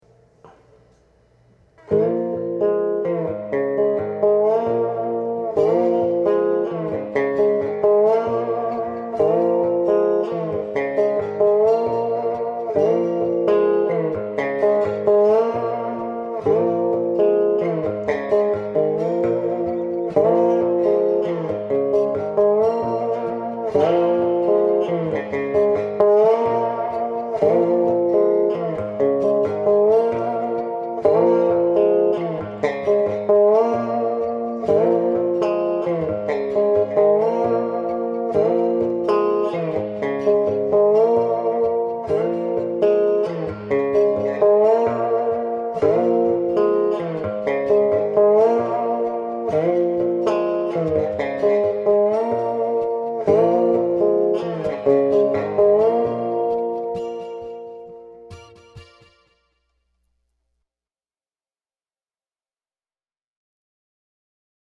Real subtle, you can hear it better with headphones.
I added some ambient acoustic guitar